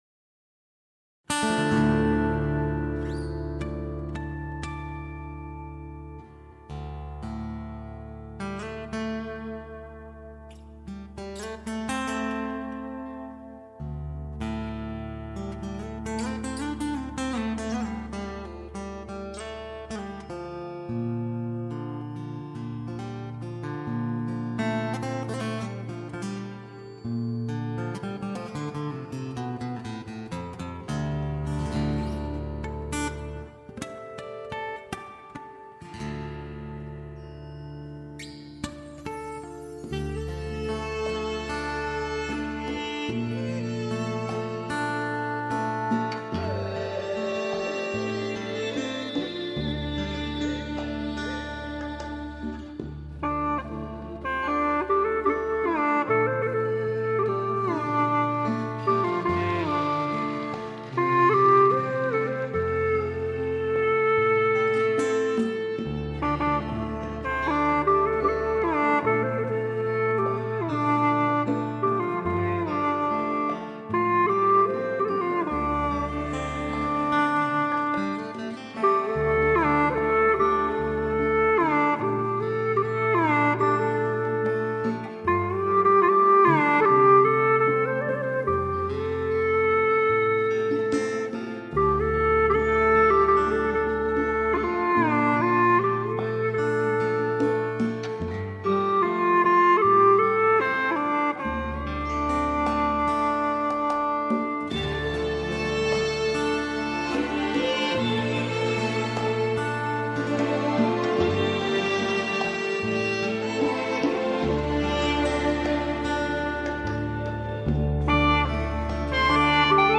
调式 : F 曲类 : 流行